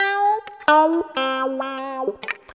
64 GUIT 1 -R.wav